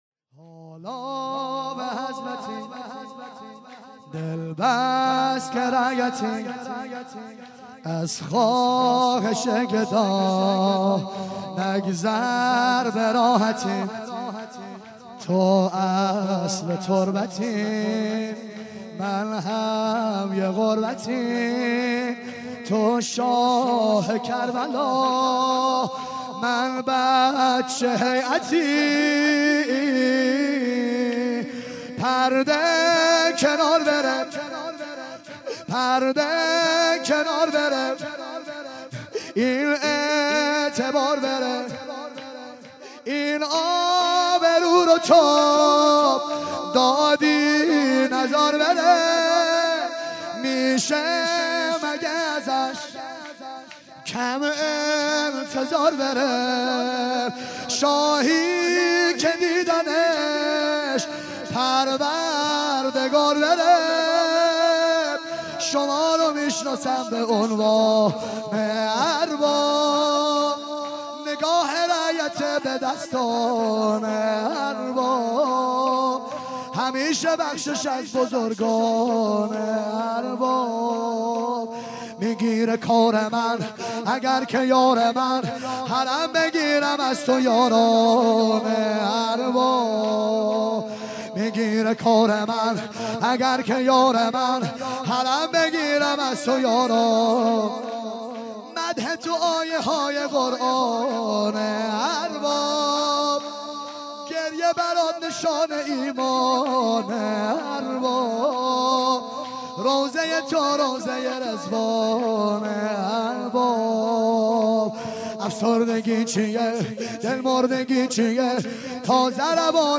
12- حالا به حضرتی - شور پایانی